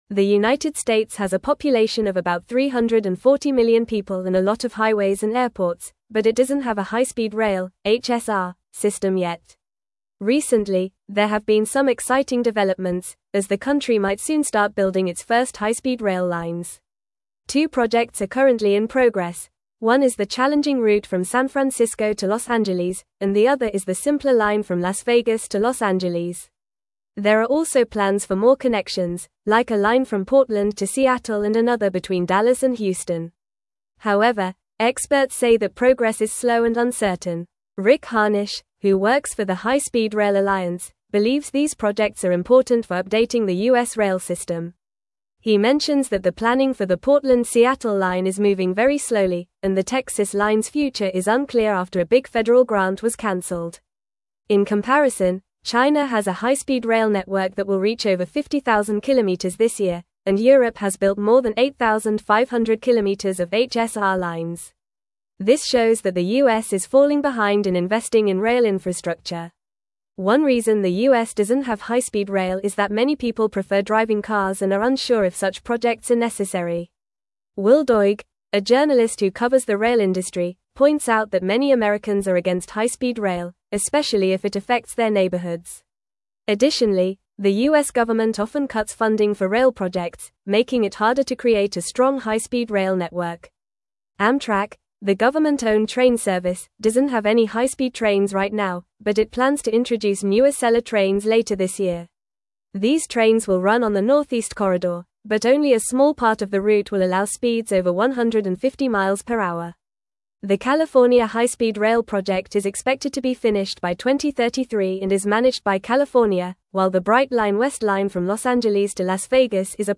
Fast
English-Newsroom-Upper-Intermediate-FAST-Reading-Challenges-and-Progress-of-High-Speed-Rail-in-America.mp3